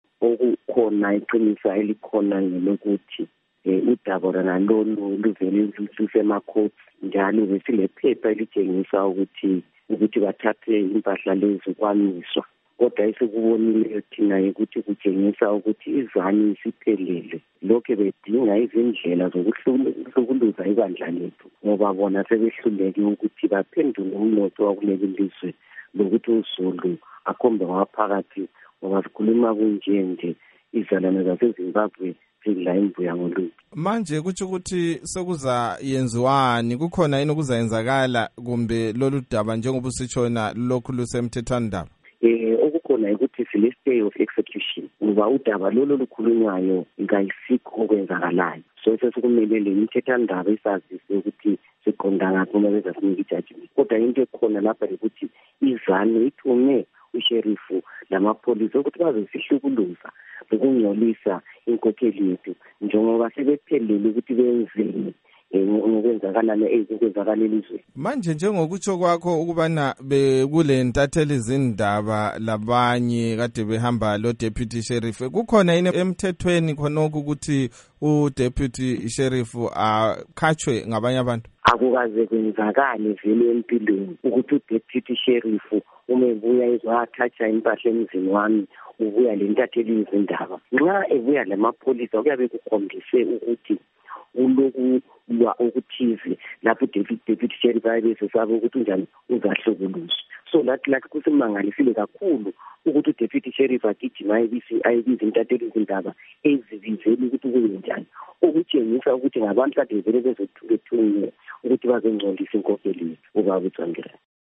Ingxoxo loNkosazana Thabitha Khumalo